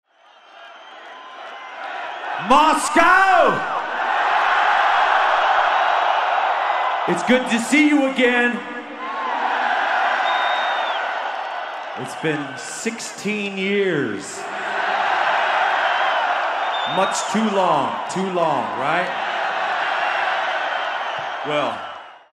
То ли дело было в Москве.